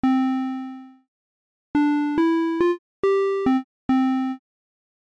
もうひとつ覚えておきたいのが、そこからSlopeを0%に設定しDecayを70%等適当に調節した、ピアノタイプのエンベロープ。
●ピアノエンベロープの音色サンプル
pianotype.mp3